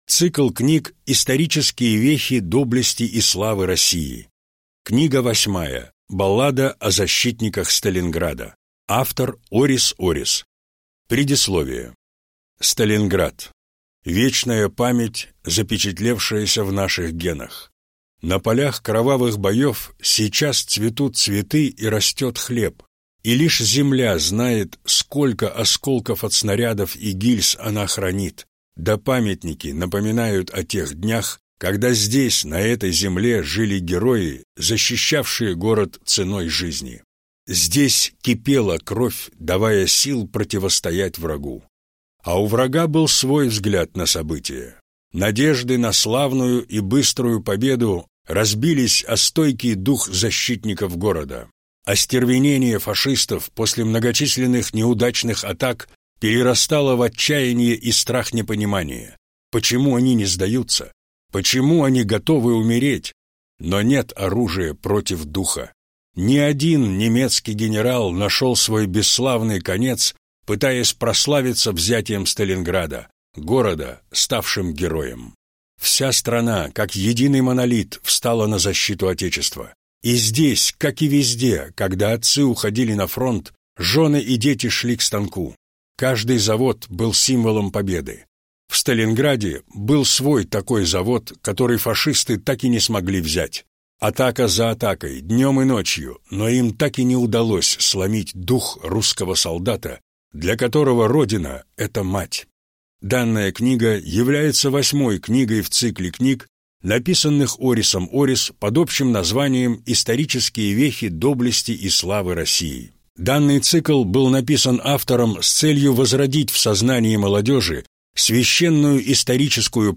Аудиокнига Баллада о защитниках Сталинграда | Библиотека аудиокниг